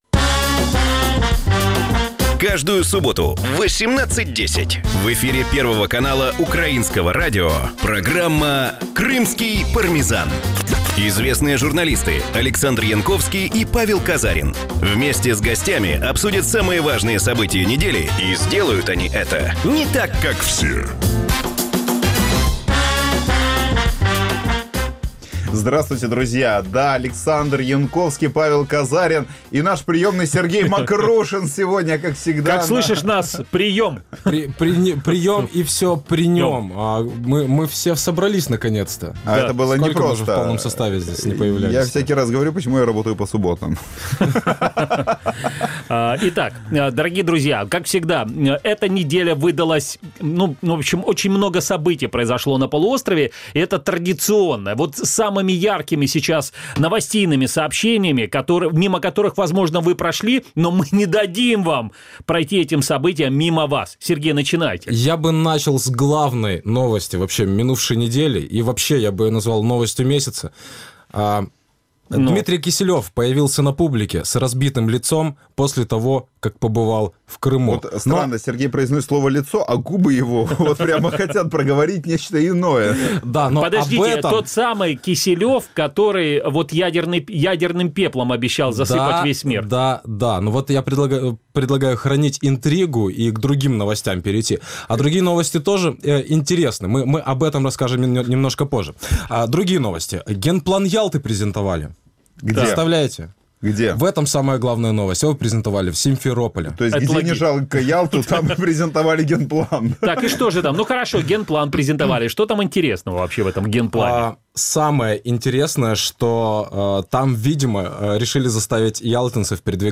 Програма звучить в ефірі Радіо Крим.Реалії. Час ефіру: 18:10 – 18:40.